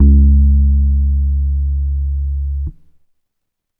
10-C#.wav